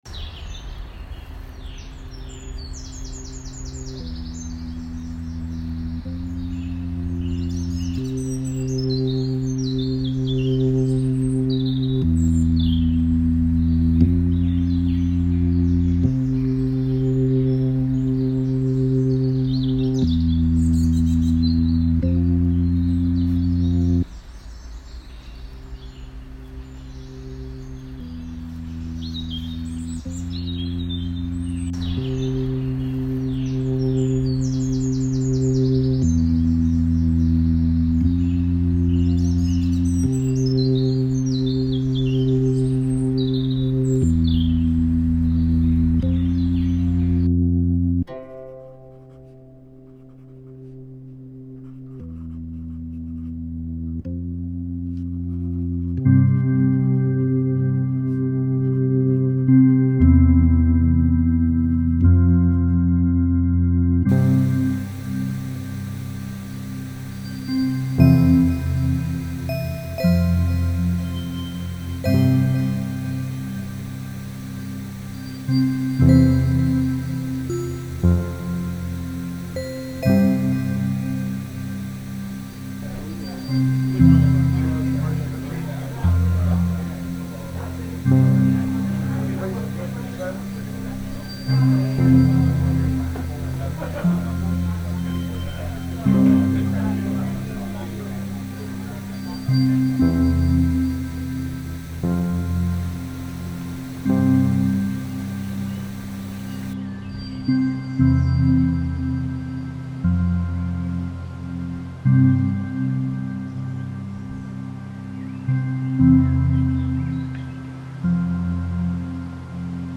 InternshipSoundscape_v2.mp3